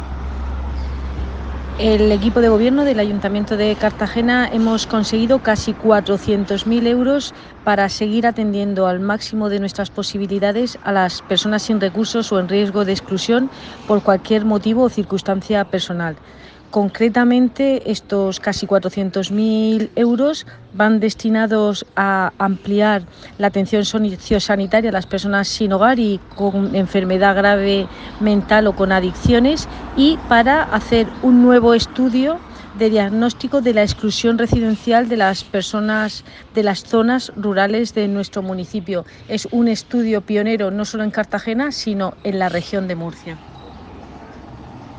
Volver al listado Multimedia Declaraciones de la edil Cristina Mora.